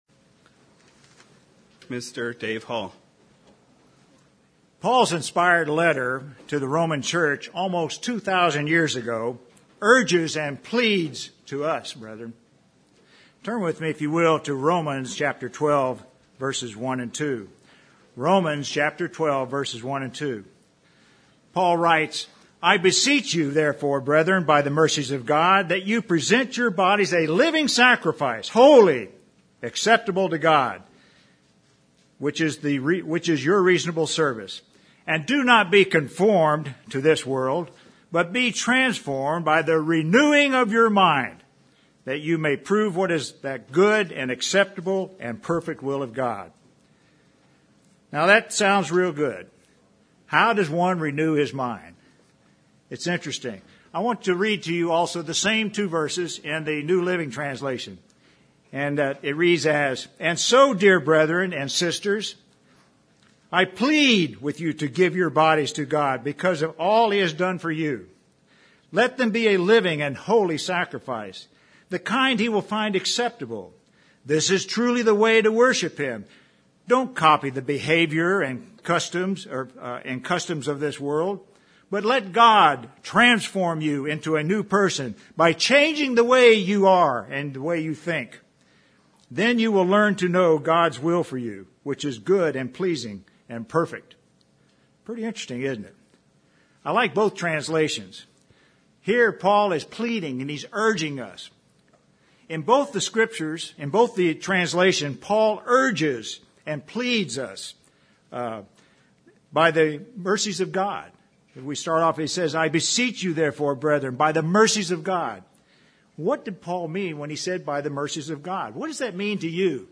Sermons
Given in Redlands, CA